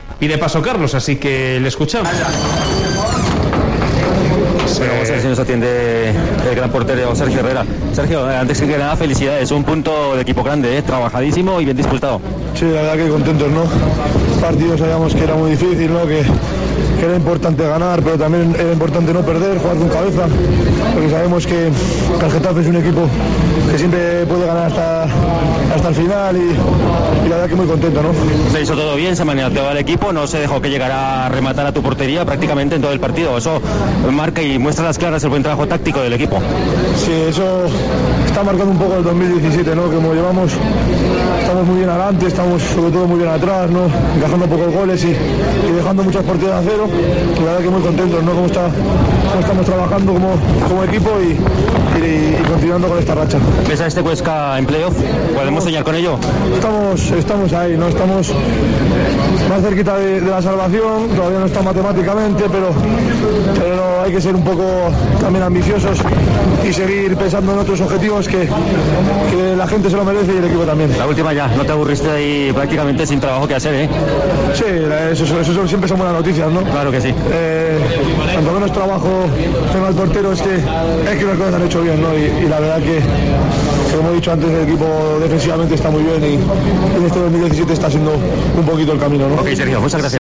Declaraciones de Sergio Herrera en zona mixta